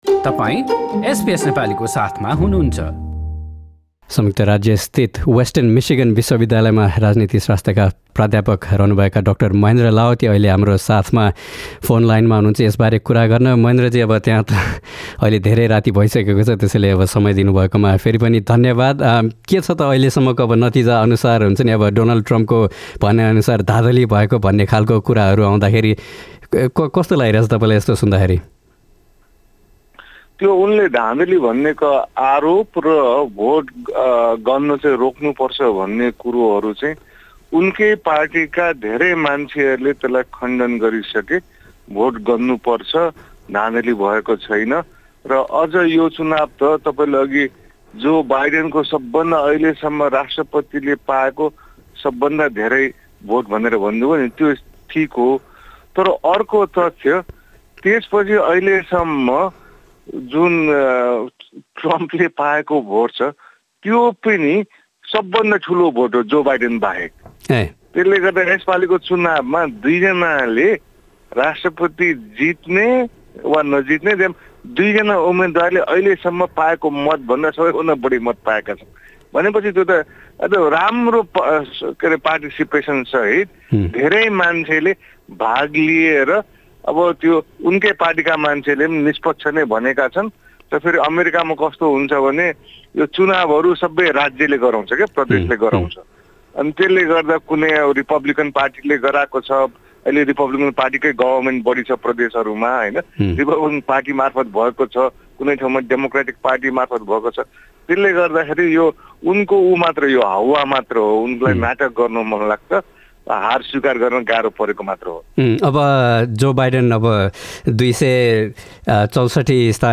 हाम्रो कुराकानी